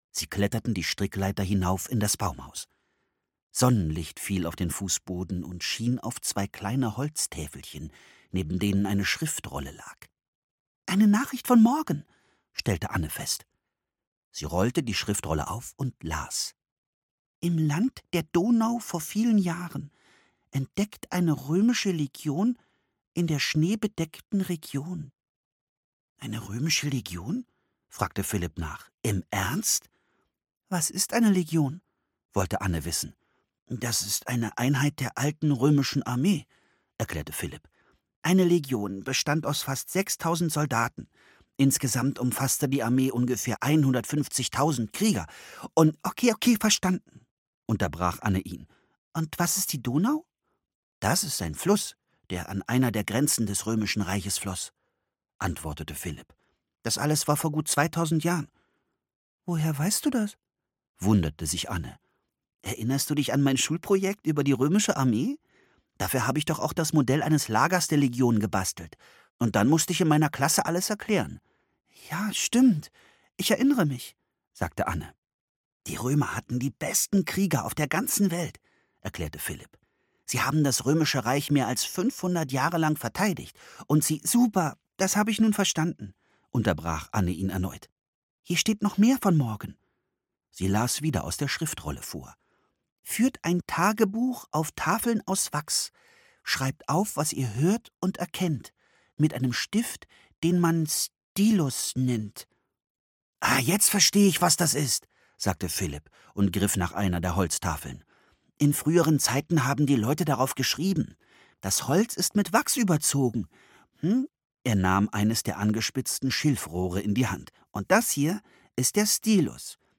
Der römische Spion (Das magische Baumhaus 56) - Mary Pope Osborne - Hörbuch - Legimi online